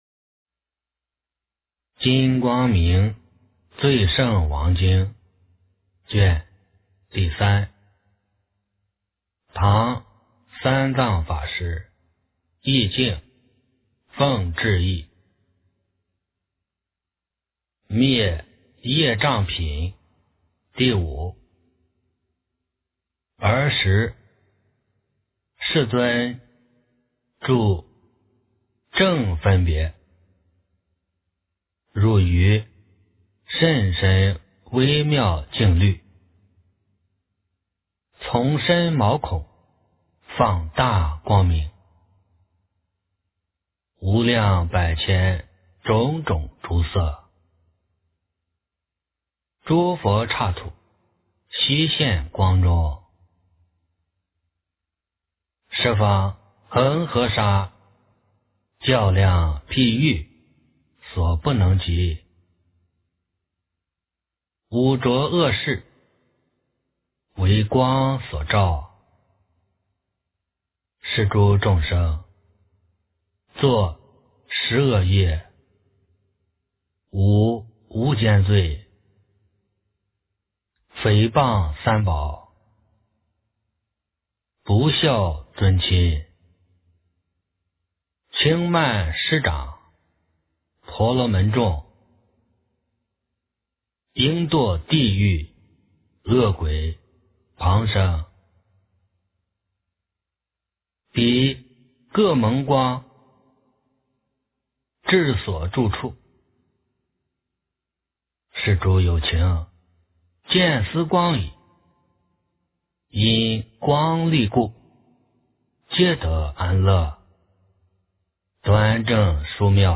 金光明最胜王经3 - 诵经 - 云佛论坛